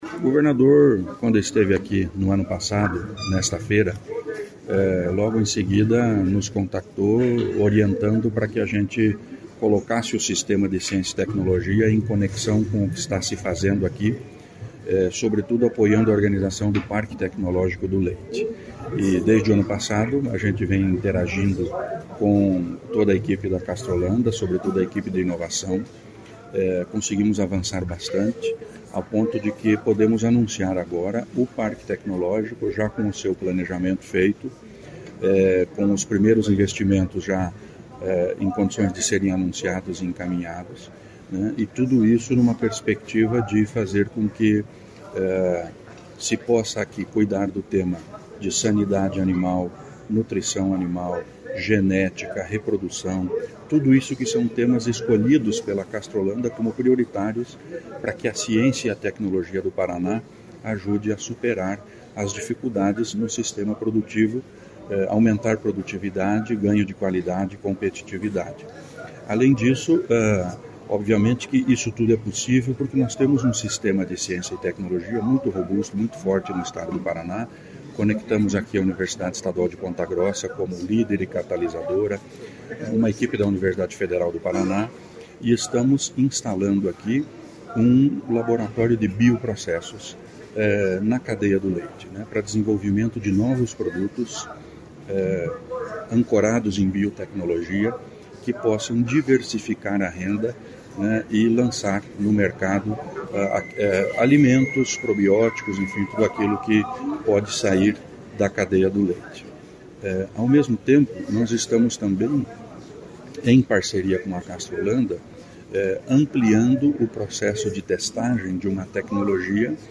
Sonora do secretário da Seti, Aldo Bona, sobre o anúncio laboratório de biotecnologia do leite e solução europeia para o agro em Castro